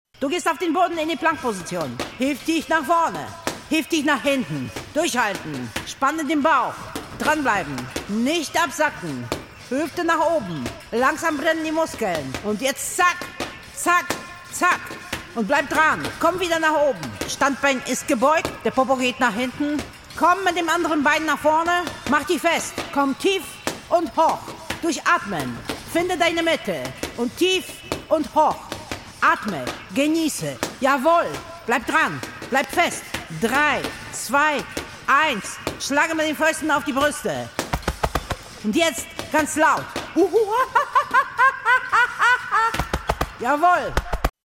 Minihörspiele, die für abwegige Gedanken, neue Wahrnehmungen und intelligenten Humor werben.